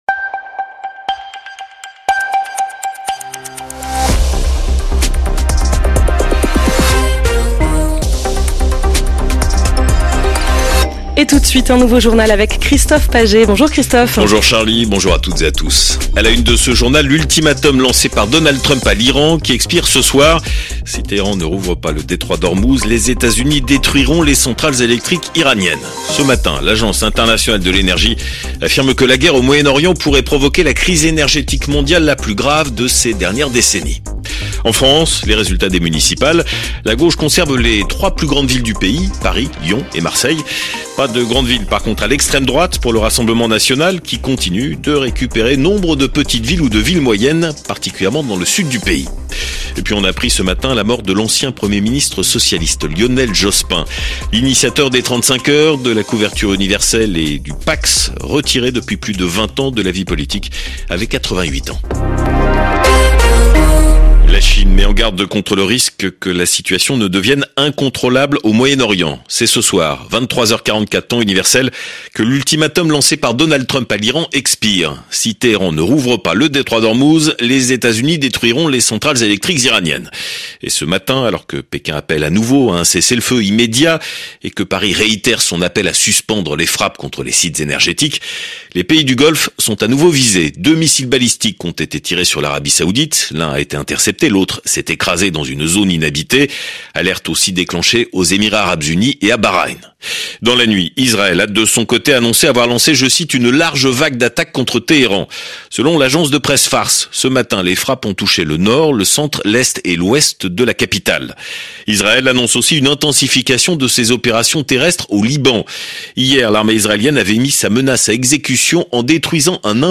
Flash infos 23/03/2026
L'infos sur radio COOL DIRECT de 7h à 21h